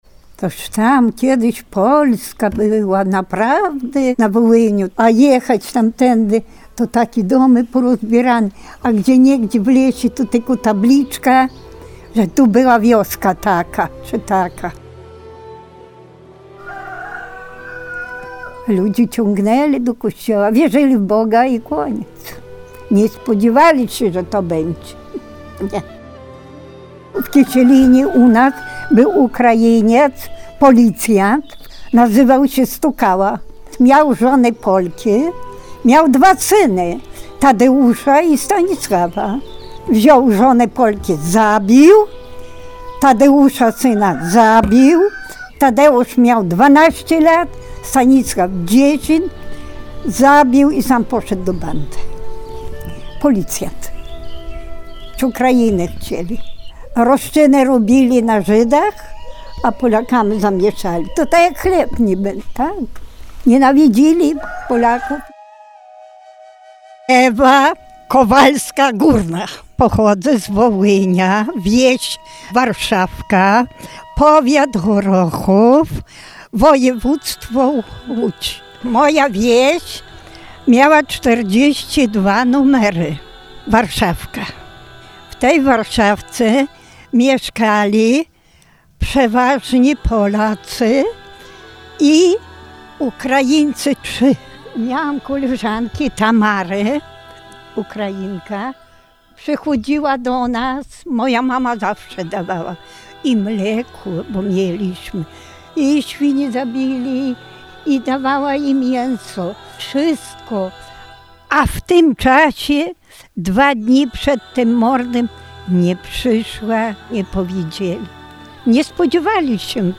Narodowy Dzień Pamięci Ofiar Ludobójstwa dokonanego przez ukraińskich nacjonalistów na ludności polskiej II Rzeczpospolitej obchodzony jest co roku 11 lipca. Relacje świadków tamtych tragicznych dni prezentowaliśmy na antenie katolickiego Radia Rodzina.